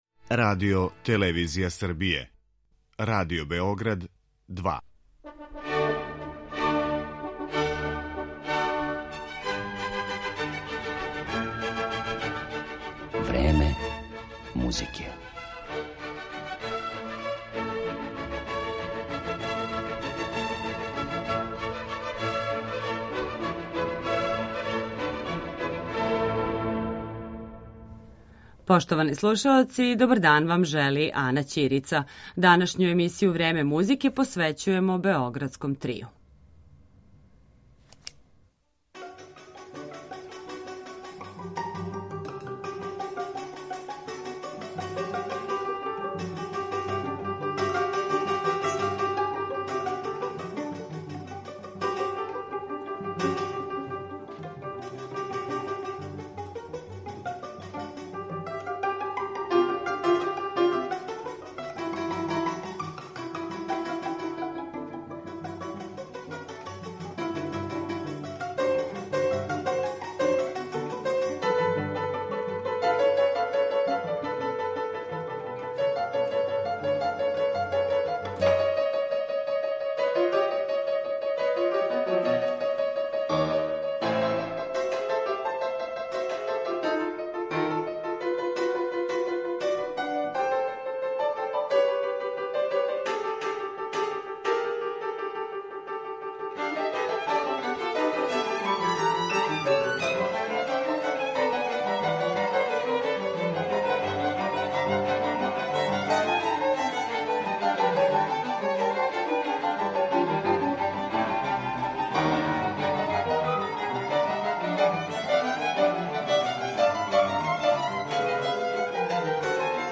Тим поводом, наши гости у Студију 2 Радио Београда биће